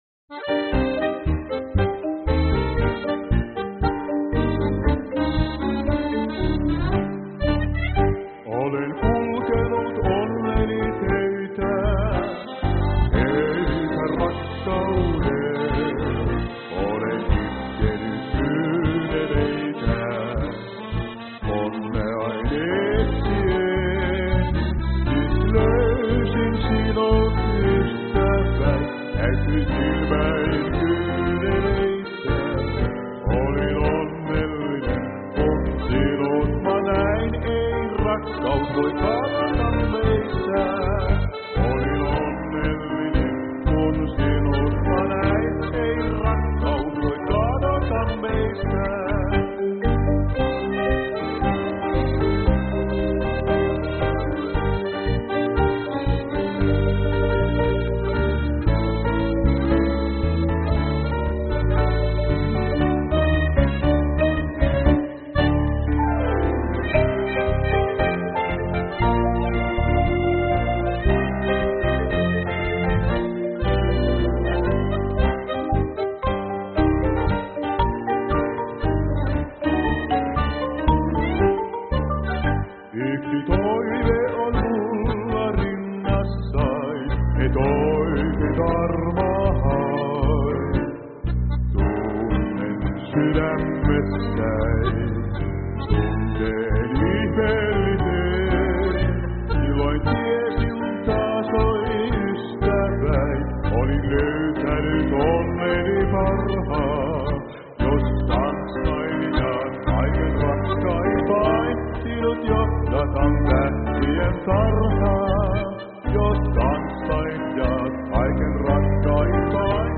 Pianossa